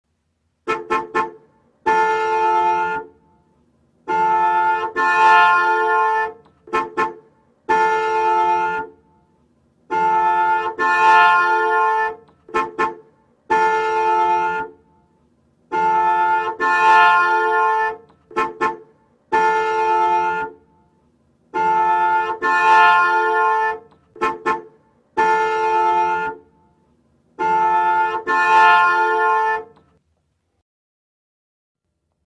3D spatial surround sound "Auto signal"
3D Spatial Sounds